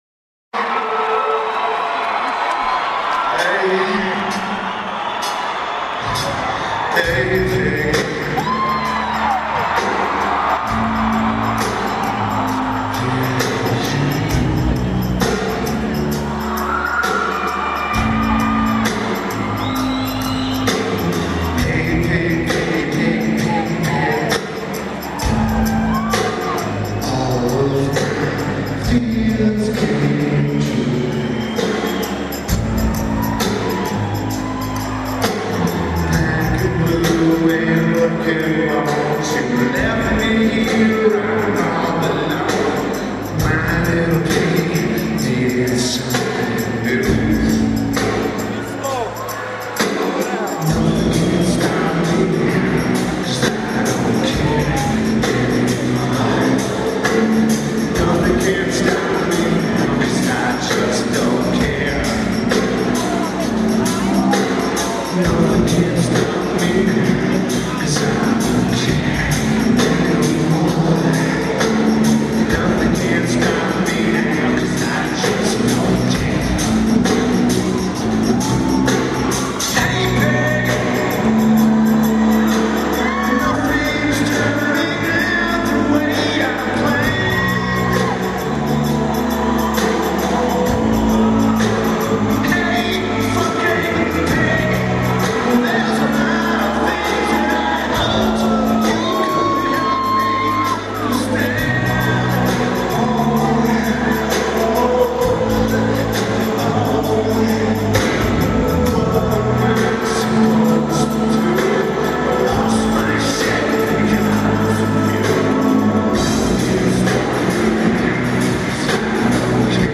The Palace of Auburn Hills
Lineage: Audio - AUD (Unknown)
Also, this is a lossy source.